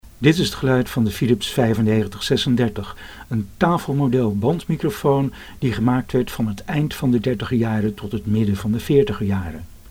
Het tafelmodel werd geadverteerd als 'van de hoogste kwaliteit' ,'speciaal ontwikkeld voor spraak' en 'waar een statief niet nodig is'.